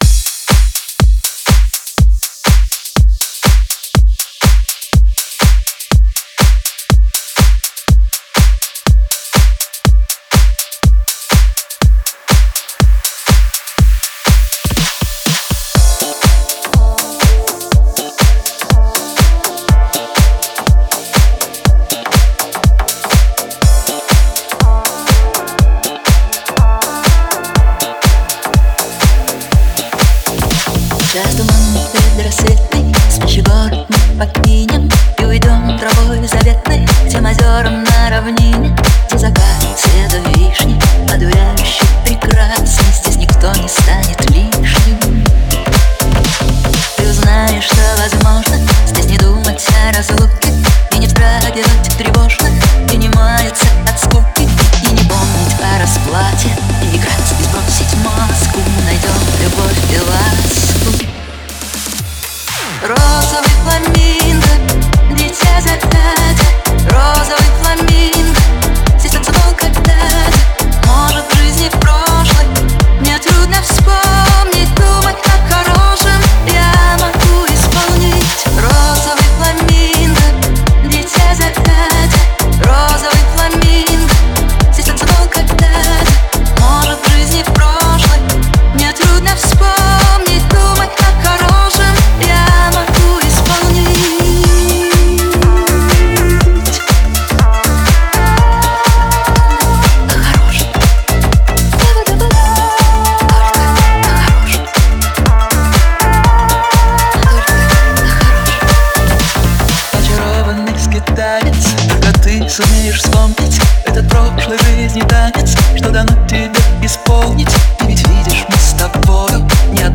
Жанр: Pop, Dance, Other